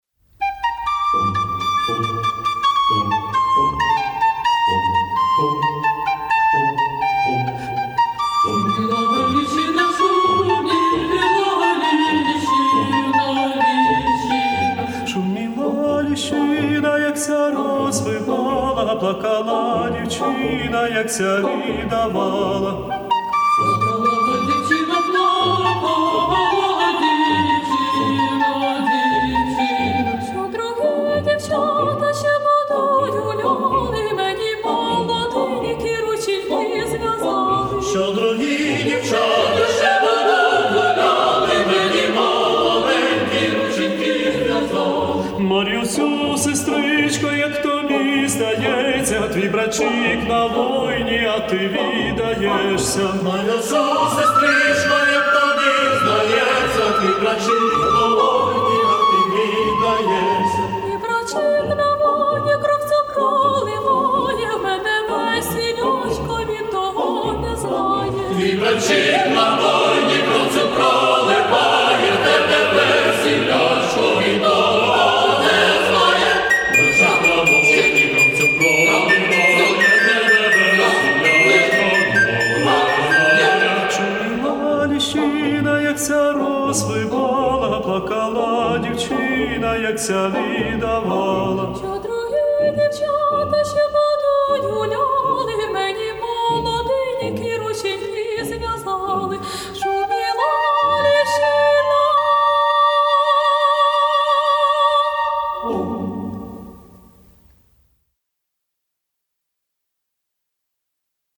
Українська народна пісня